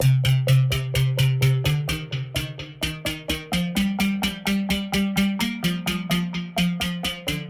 标签： 128 bpm House Loops Guitar Electric Loops 1.26 MB wav Key : C
声道立体声